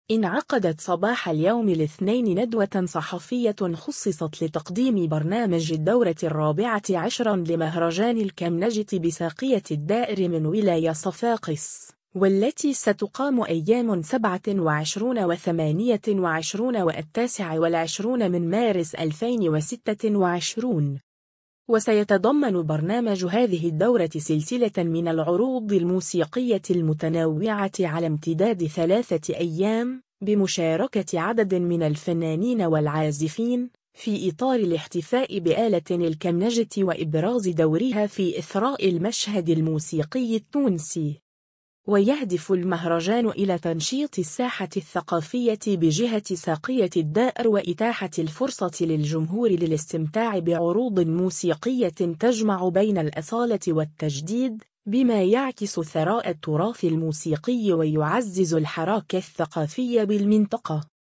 صفاقس: ندوة صحفية لتقديم برنامج الدورة الـ14 لمهرجان الكمنجة